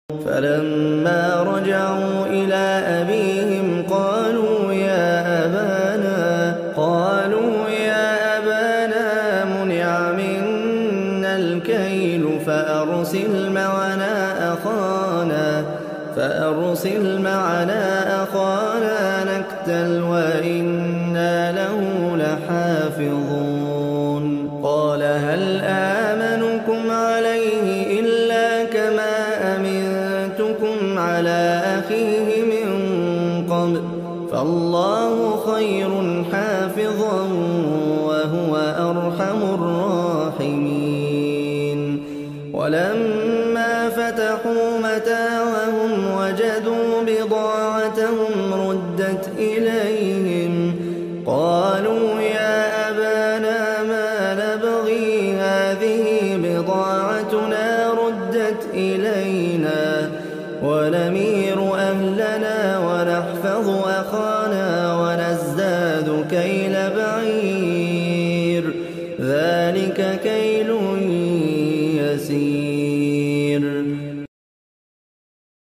اروع التلاوات احسن تلاوة